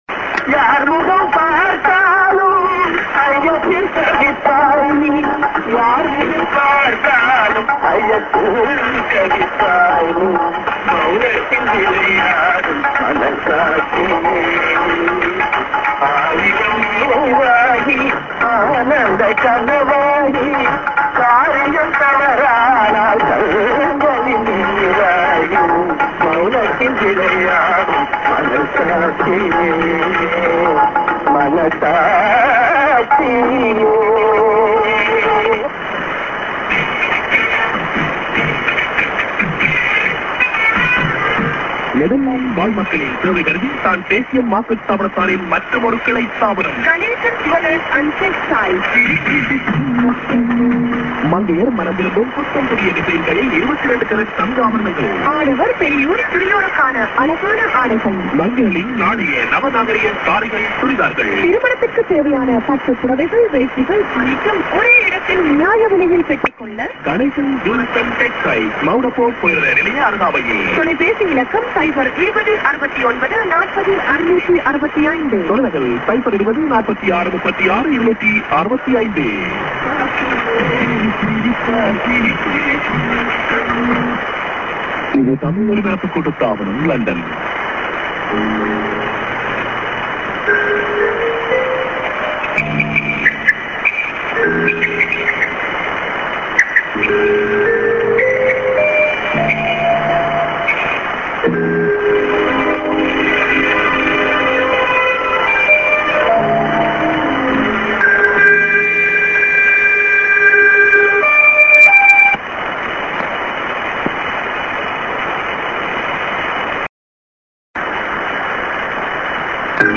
via DTK Julich St. music->01'25:ID(man)->music->03'20":ID(women)->music->